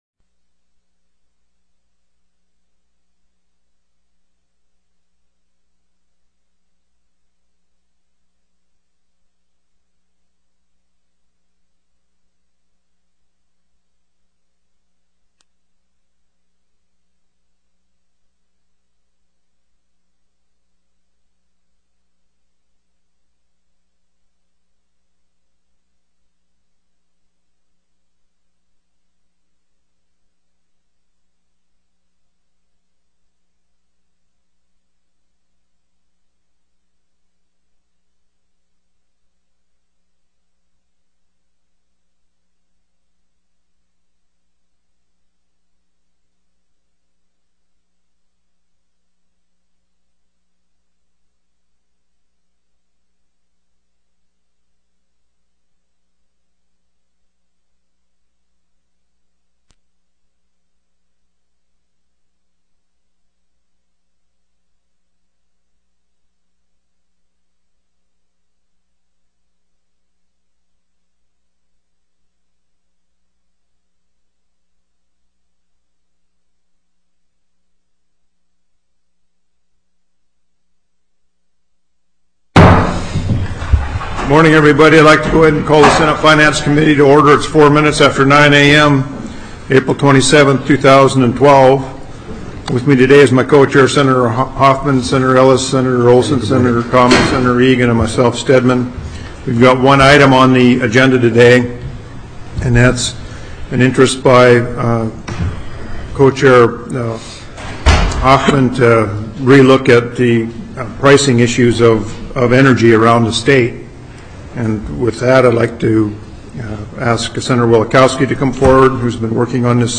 04/27/2012 09:00 AM Senate FINANCE
Co-Chair Stedman called the Senate Finance Committee meeting to order at 9:04 a.m.